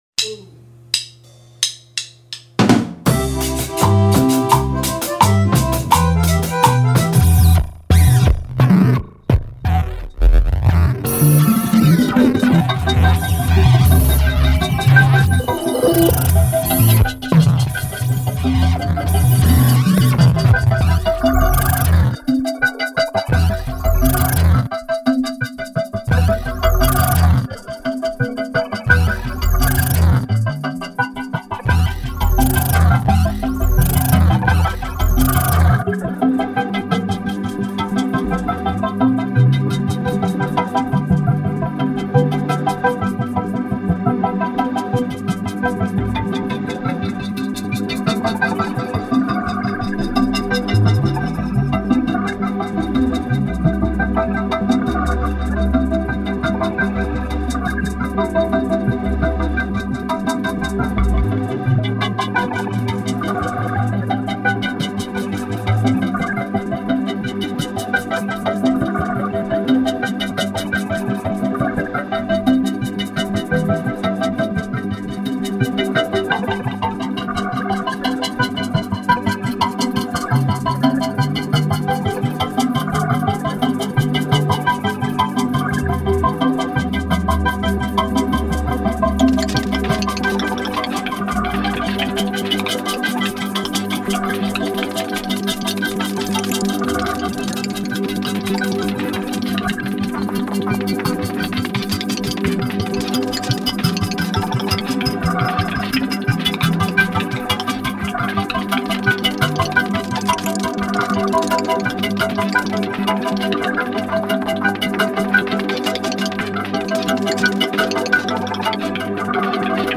minimalismo
electronic norteña